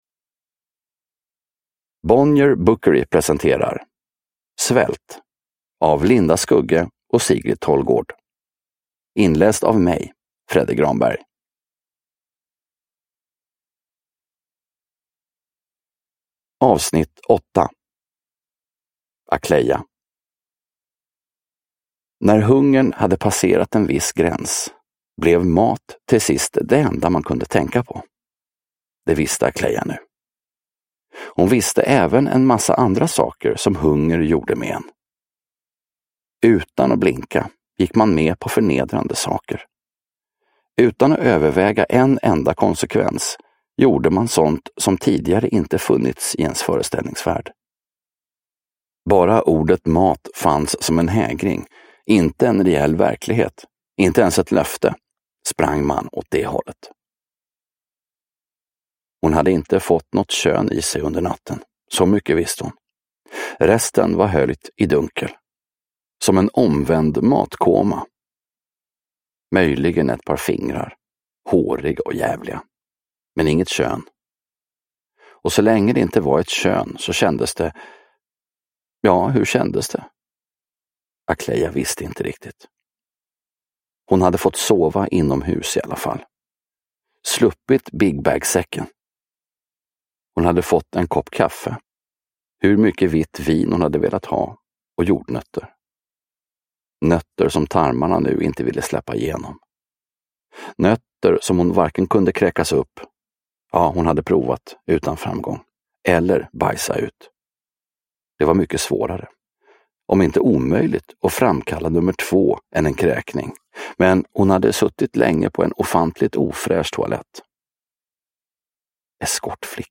Svält. S1E8 – Ljudbok – Laddas ner